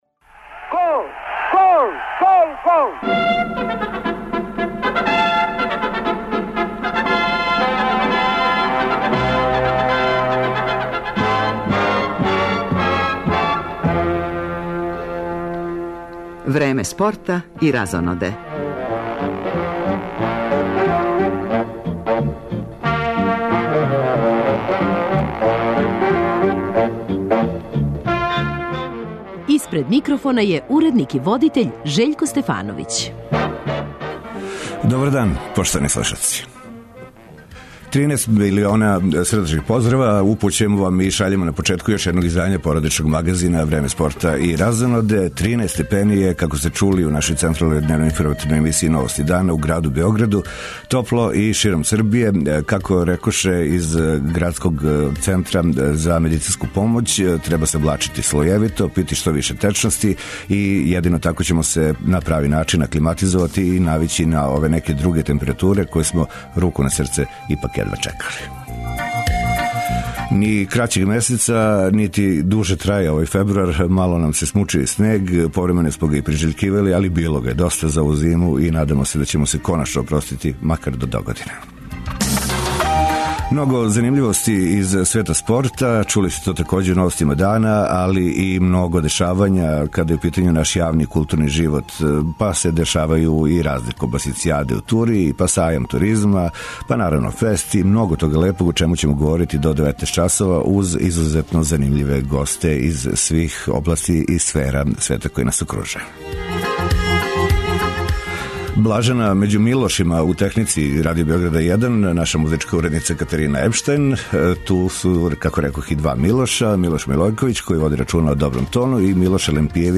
Из Лондона нам у студио долази глумица Весна Станојевић, с којом ћемо причати о раним радовима, попут филма "Последњи круг у Монци" и серије "Доме, слатки доме", до учешћа у актуелним тв серијама наше куће.